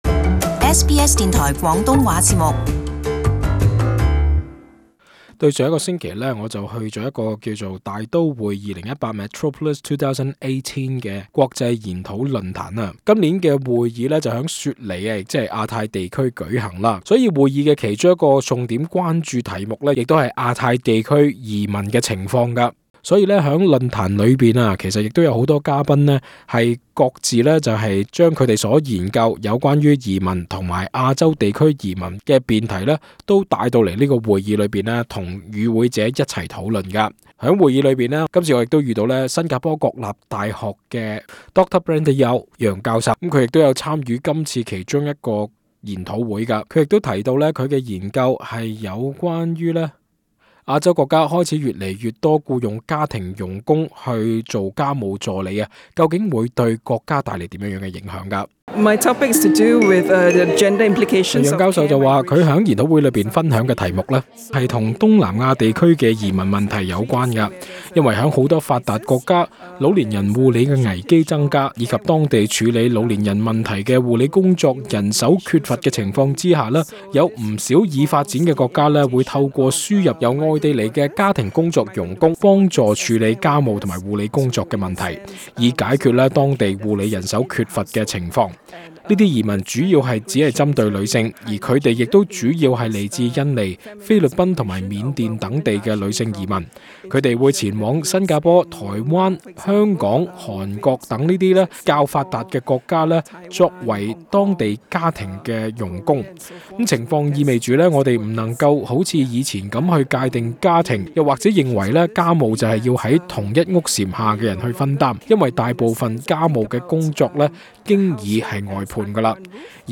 【特別專訪】大都會2018論壇－移民會否向亞洲地區轉移？